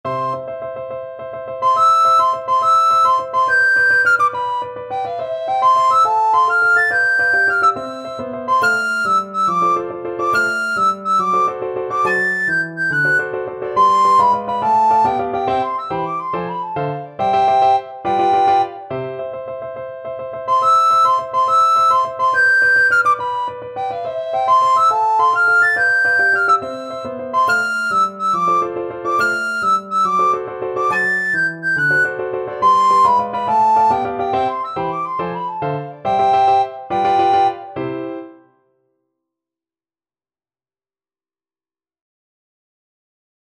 Free Sheet music for Soprano (Descant) Recorder
6/8 (View more 6/8 Music)
Allegro Vivace .=140 (View more music marked Allegro)
C major (Sounding Pitch) (View more C major Music for Recorder )
Classical (View more Classical Recorder Music)
mendelssohn_italian_REC.mp3